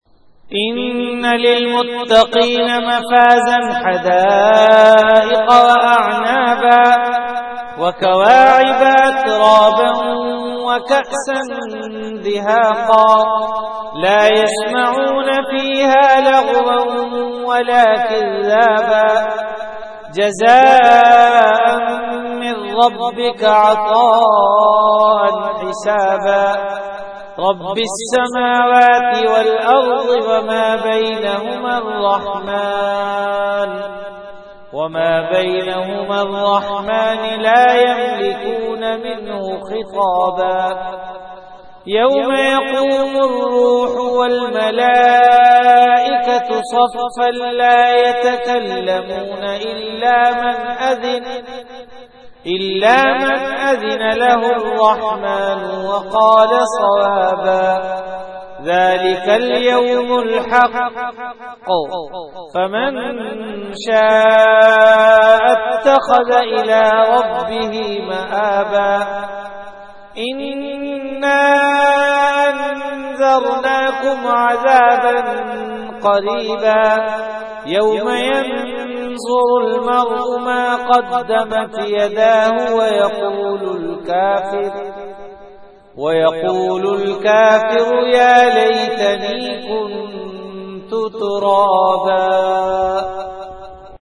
CategoryTilawat
Event / TimeAfter Isha Prayer